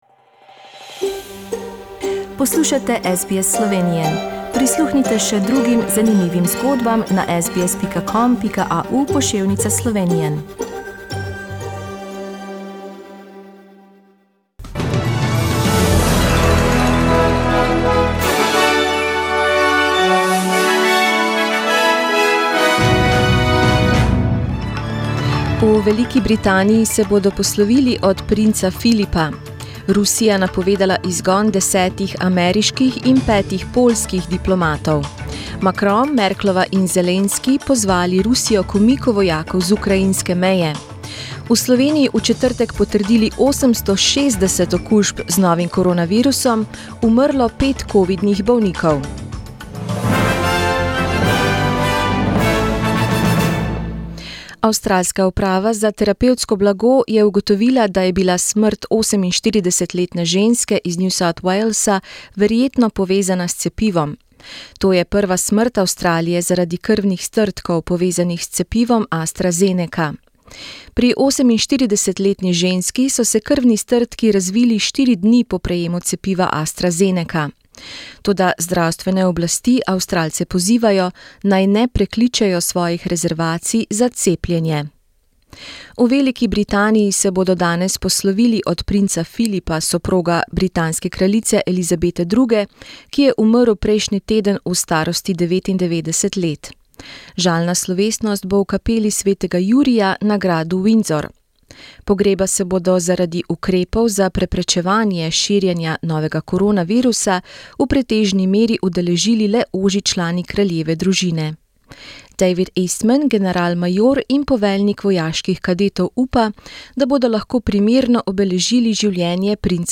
SBS News in Slovenian - 17th April, 2021